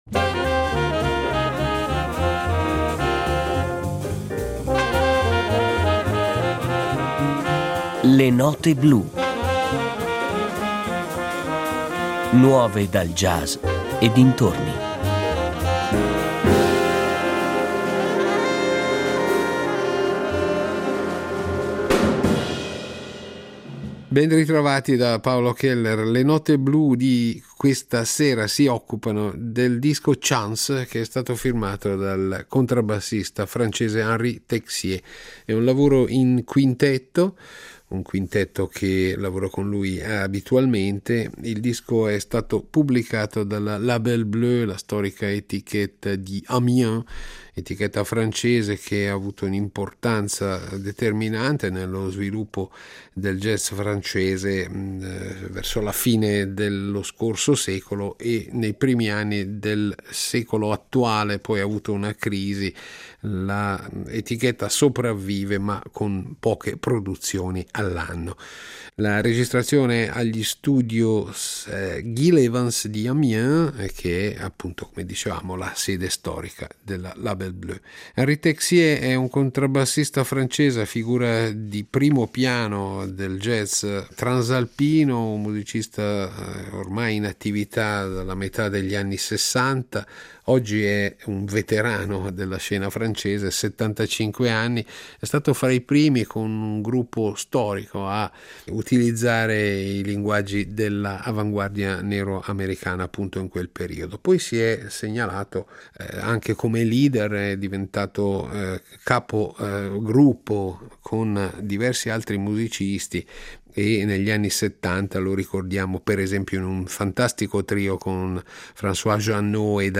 contrabbassista
Il mondo delle novità legate al jazz e dintorni